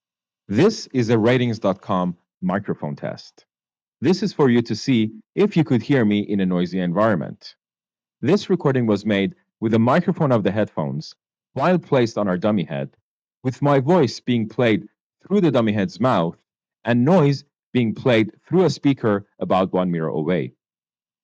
For headphones with microphones, we use the same voice sample to record a demonstration of the mic's sound by having it play back from a speaker located where a person's mouth is, and then use the headphones' microphone to capture the audio.
For Noise Handling, we run the same voice sample for two tests with different types of noise recordings playing from speakers in the room to test how well the microphone system separates and prioritizes voices above background noise.
Audeze Maxwell Wireless demo with noise. We do the same process to record how effectively the mic rejects real-world sounds like other people talking nearby and subway trains with a standardized noise soundtrack, as with the Audeze Maxwell Wireless again.
sam-noise.mp3